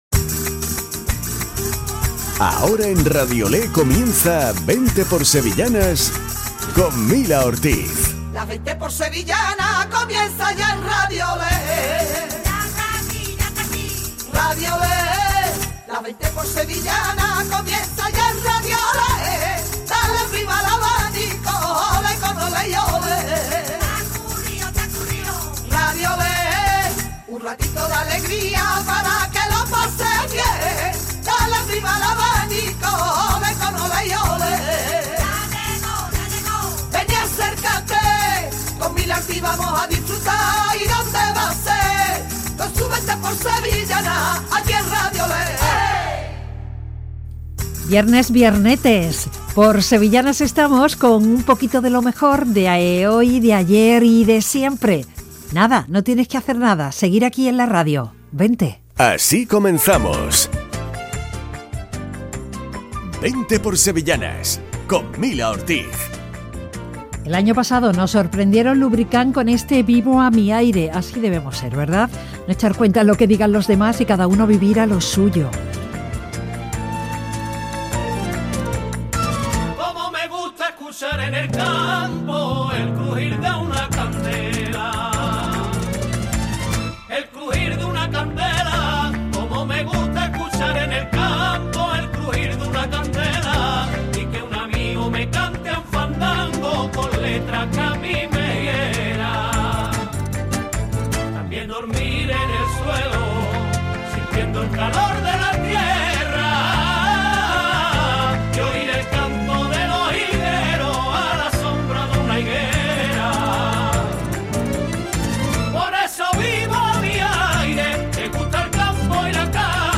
Programa dedicado a las sevillanas.